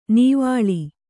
♪ nīvāḷi